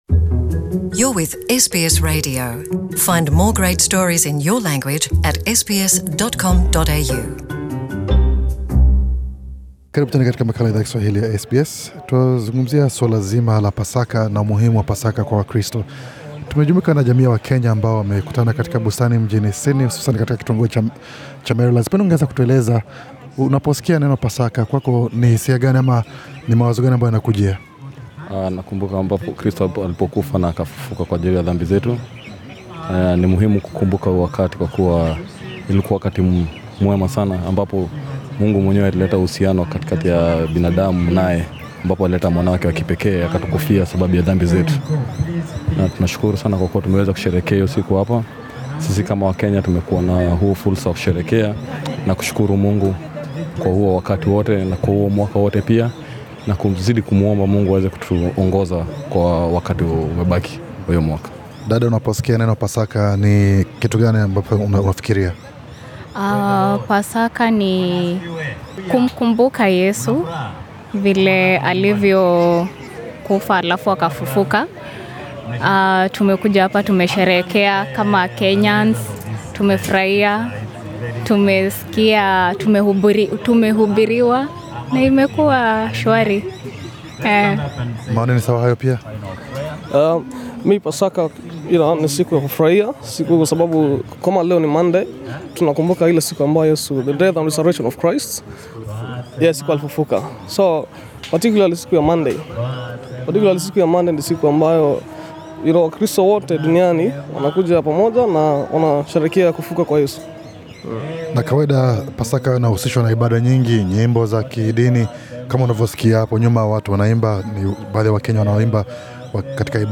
Pasaka ni sherehe za maadhimisho ya kumbukumbu ya ufufuo wa Yesu kutoka kwa wafu, kama ilivyoelezwa katika Agano Jipya, ilitokea siku ya tatu baada ya kumzika kwake baada ya kusulubiwa kwake na Warumi huko Kalvari. Hapa Australia, Jumuiya ya Wakenya walikusanyika pamoja huko Merrylands kusherehekea siku hii kubwa.